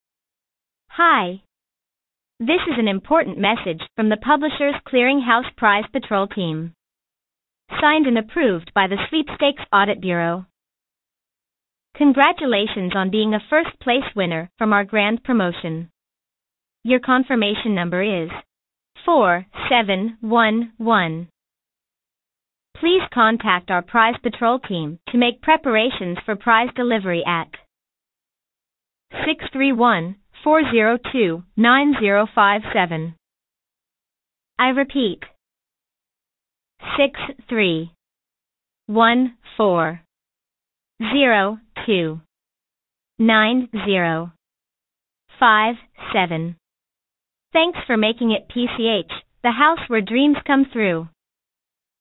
Robocall :arrow_down: